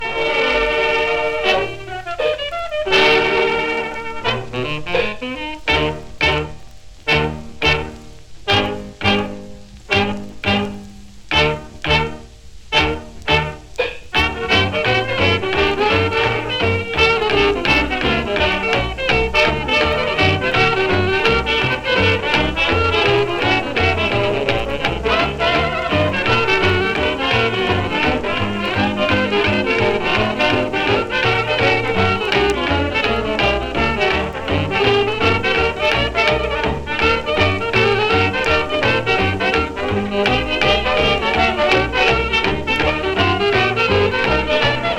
Jazz, Ragtime, New Orleans　USA　12inchレコード　33rpm　Mono